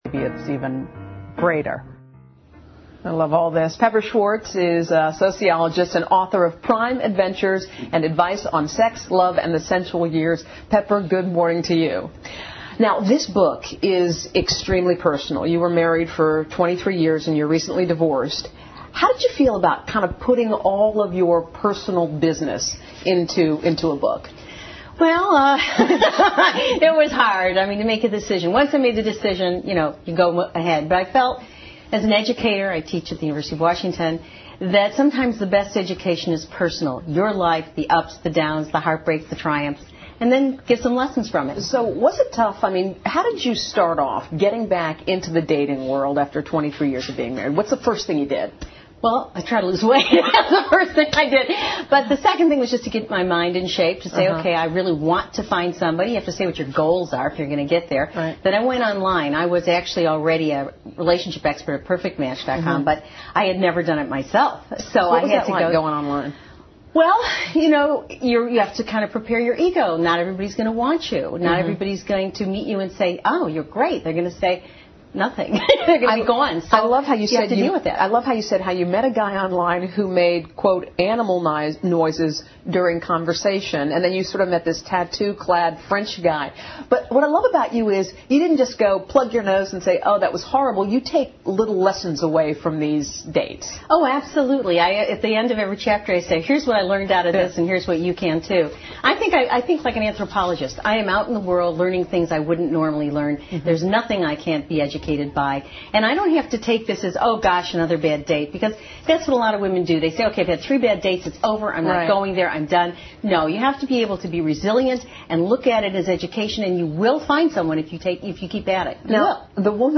访谈录 Interview 2007-07-02&07-04, 爱让我们永远年轻 听力文件下载—在线英语听力室